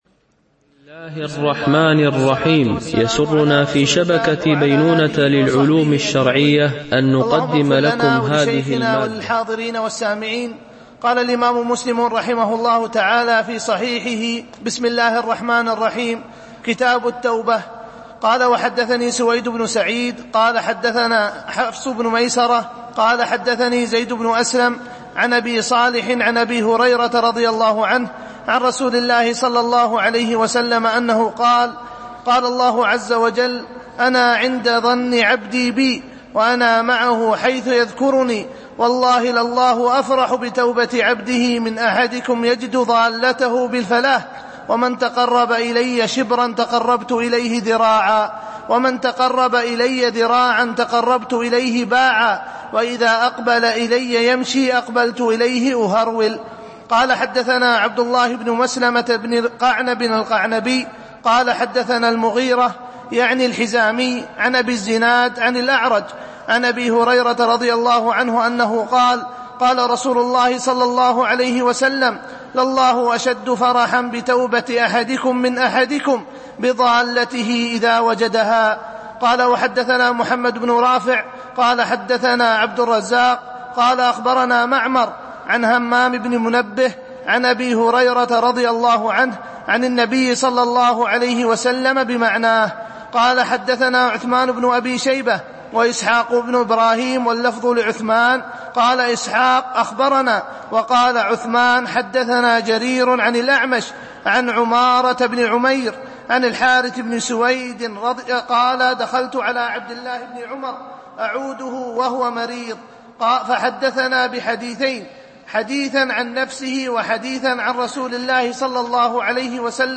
دورة علمية شرعية لمجموعة من المشايخ الفضلاء بمسجد أم المؤمنين عائشة - دبي (القوز 4)